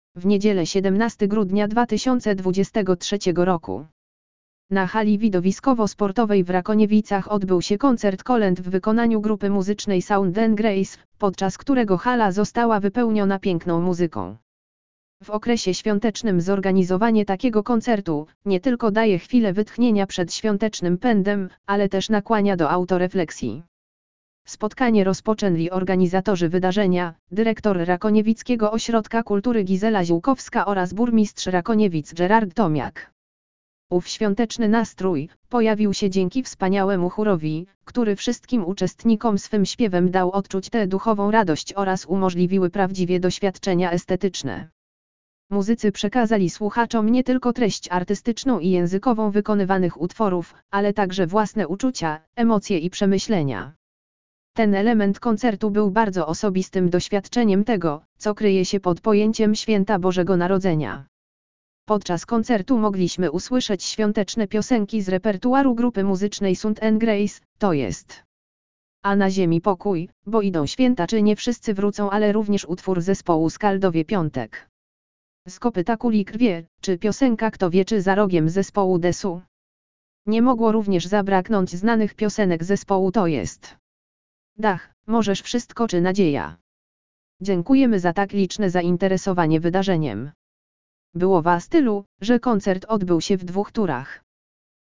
W niedzielę 17.12.2023r. na hali Widowiskowo Sportowej w Rakoniewicach odbył się Koncert Kolęd w wykonaniu grupy muzycznej Sound’n’Grace, podczas którego hala została wypełniona piękną muzyką.
Podczas koncertu mogliśmy usłyszeć świąteczne piosenki z repertuaru grupy muzycznej Sund’n’Grace, tj.
Było Was tylu, że koncert odbył się w dwóch turach!